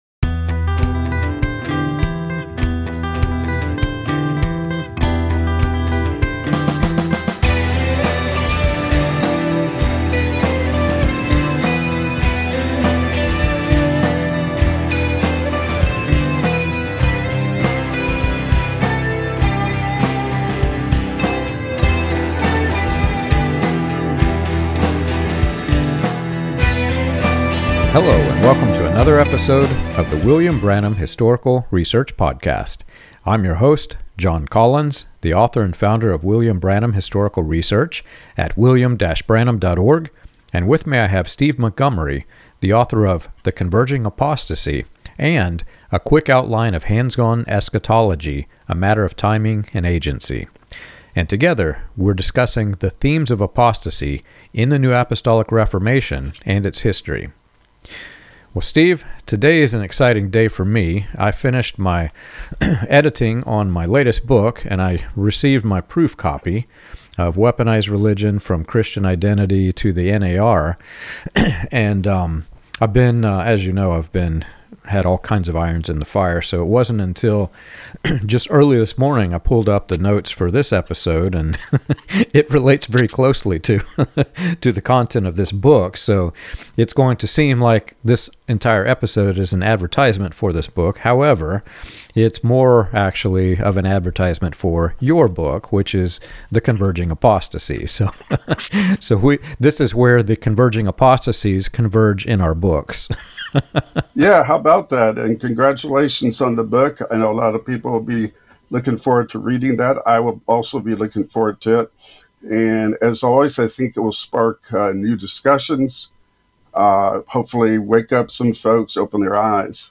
The hosts discuss the emerging doctrinal themes of the NAR, such as dominion theology and end-time prophecies, questioning their alignment with traditional Christian beliefs.